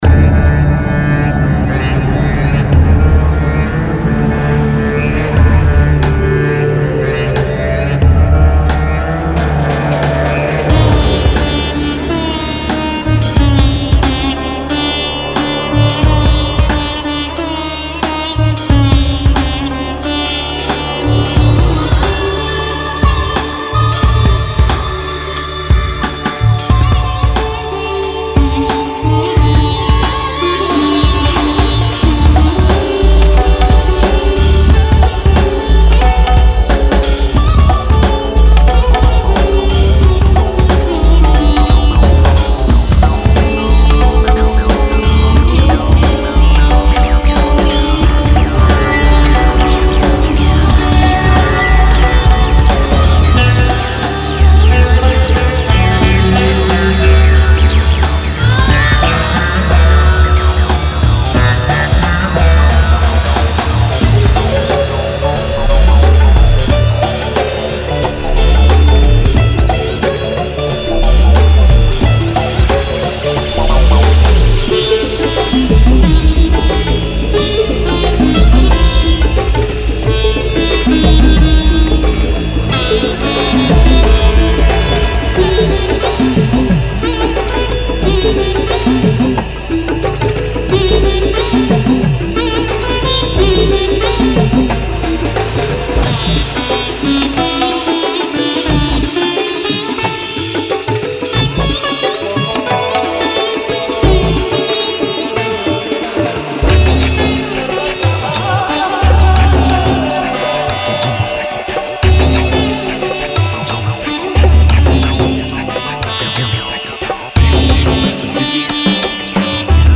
Weighing in at over 430MB, Classical Indian Sitar is your passport to an abundance of genuine Sitar melodic lines and phrases, with loops spanning over a range of keys, tempos and playing styles to guarantee you’ve got the sounds you need.
What’s more, an array of glissandos have been included to help add interest to your transitions and breakdowns plus an exclusive multi-sampled sitar instrument is also incorporated so you can jam out your own ideas on this prestigious instrument from the comfort of your chosen MIDI instrument.
Producer tips: these sounds are super clean, so you’re free to process in some really wild ways.
• Sitar Loops at 170 bpm
• Sitar Loops at 120 bpm